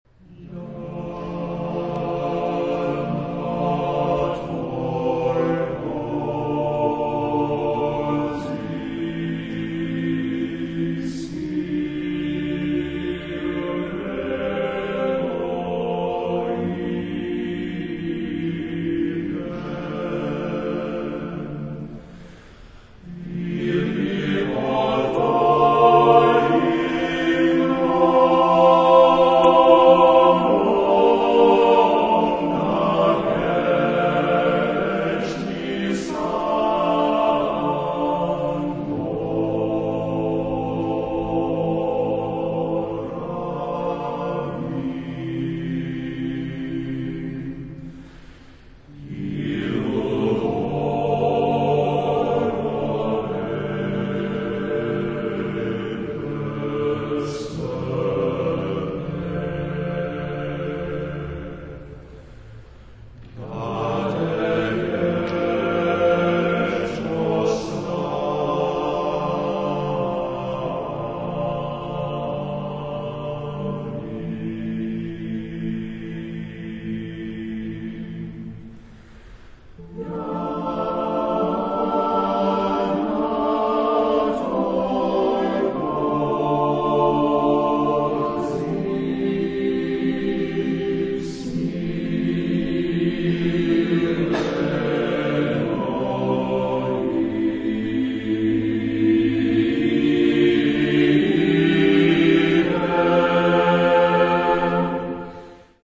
Género/Estilo/Forma: Sagrado ; Coral ; neobarroco
Carácter de la pieza : majestuoso ; calma
Tipo de formación coral: SSAATTBB  (8 voces Coro mixto )
Tonalidad : la frigio ; re eólico ; re dórico ; modal